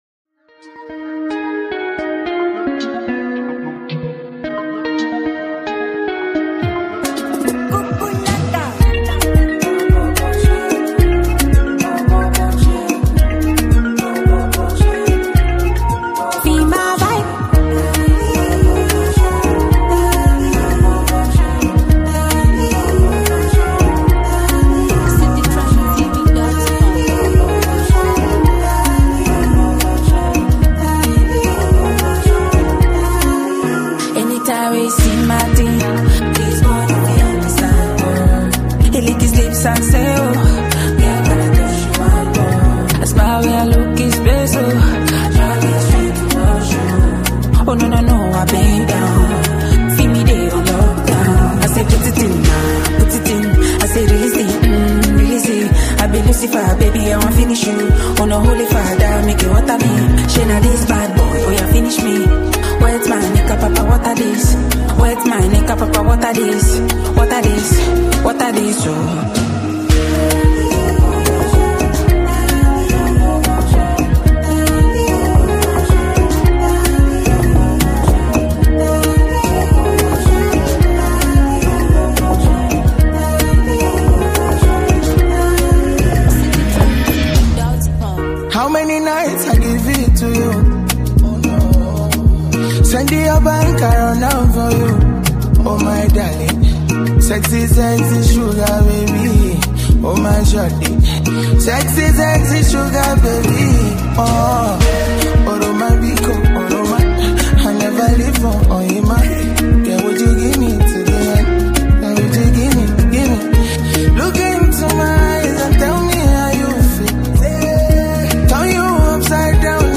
Afrobeat musical sensational artists
Over groovy drums and melodic horns